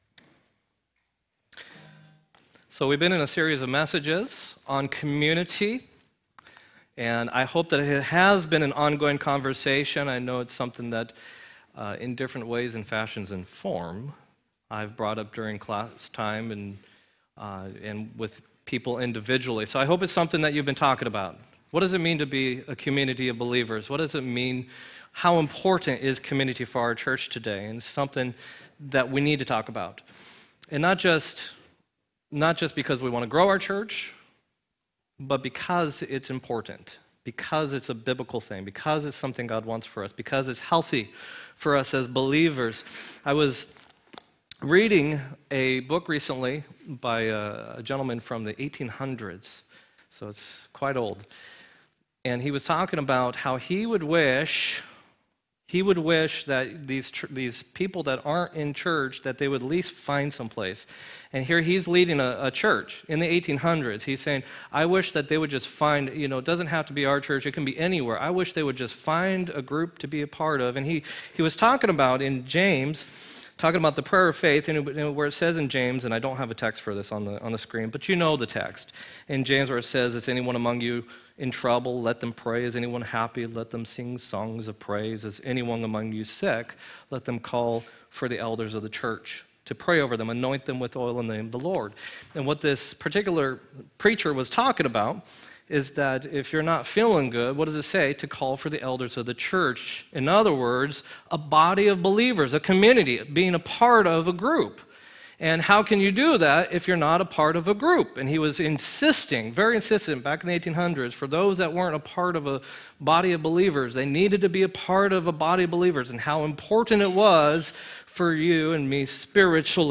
1-27-18 sermon